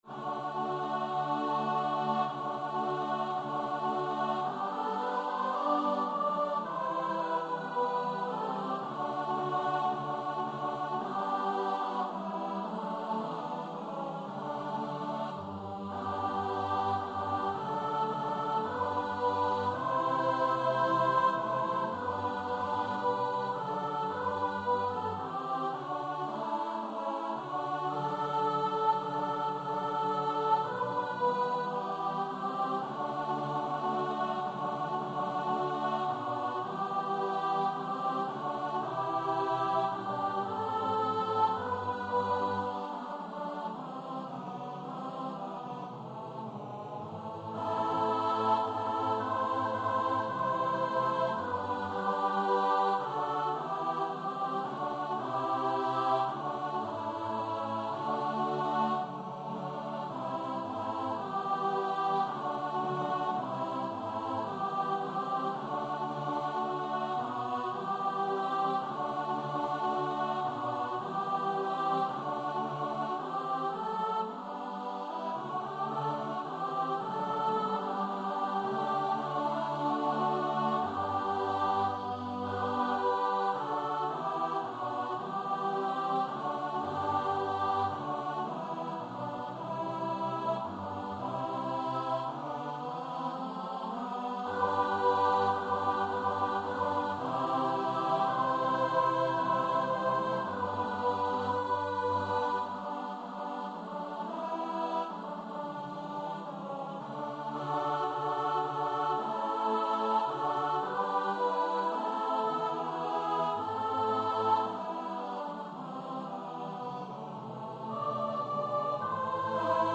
Free Sheet music for Choir
CantusAltusTenor I & IIBassus I & II
G major (Sounding Pitch) (View more G major Music for Choir )
4/2 (View more 4/2 Music)
Classical (View more Classical Choir Music)